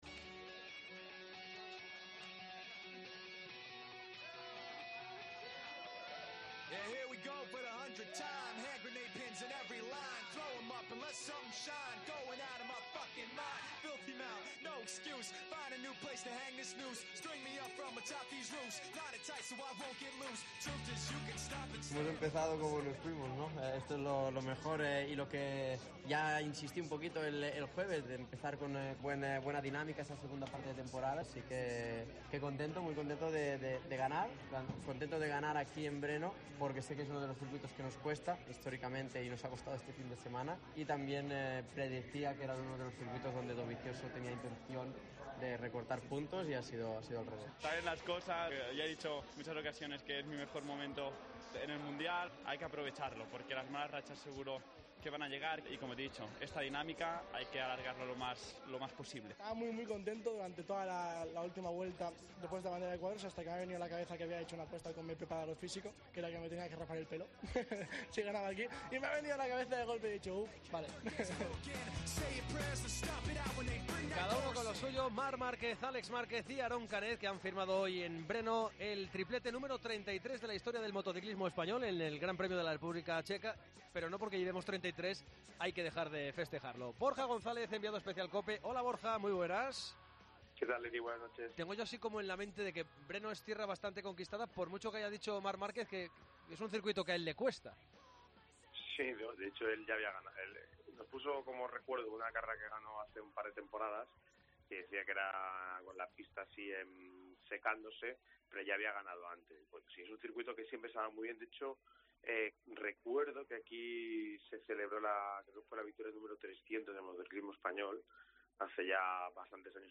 AUDIO: Gran Premio de Motociclismo de República Checa. Entrevista a Álex Márquez.
Entrevista a Carlos Sainz....